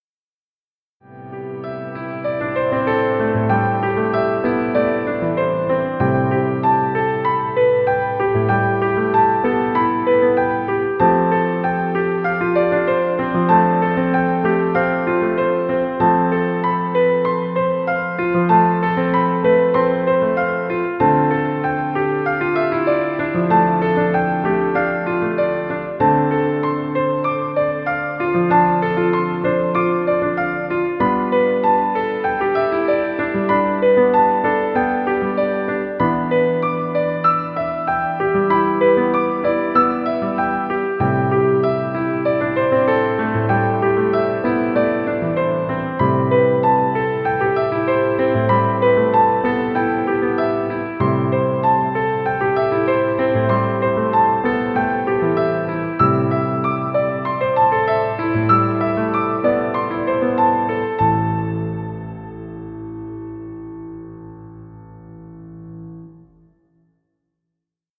Cinematic Romantic music.